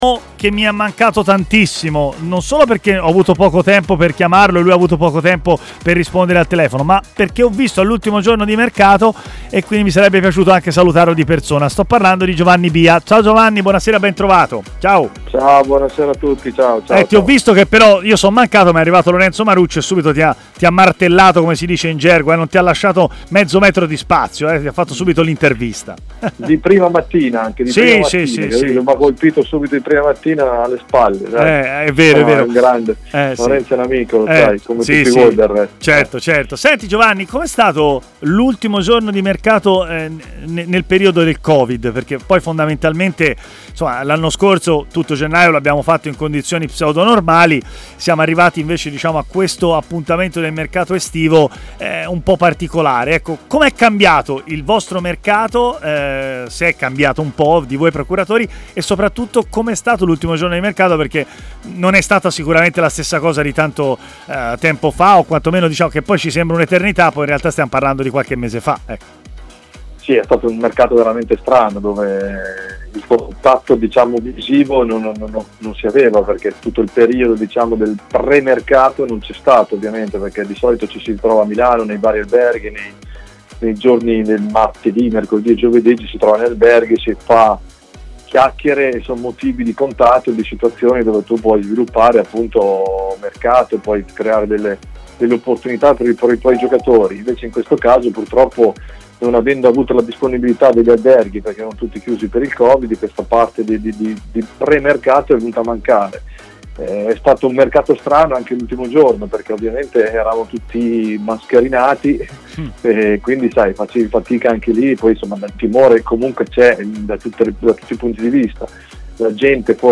si è collegato con Stadio Aperto, trasmissione di TMW Radio